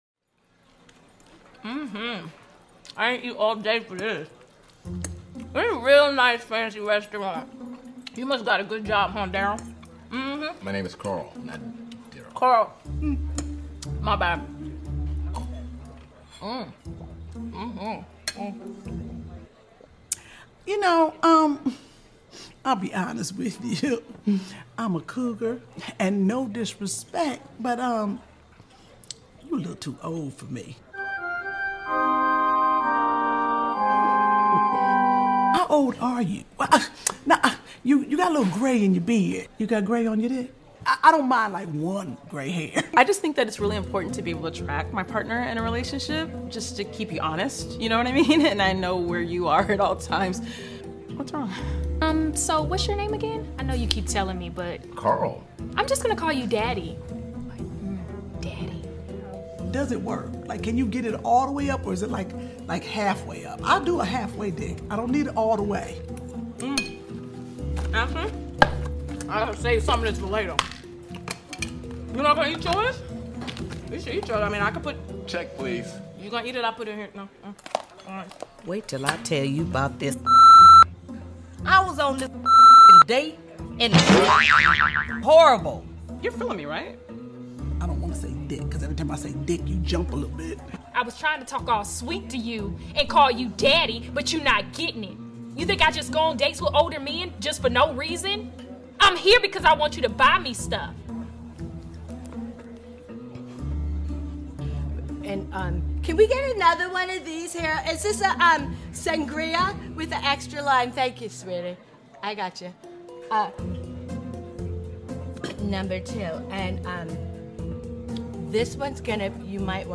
“scripted improv”